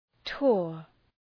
Προφορά
{tʋər}
tour.mp3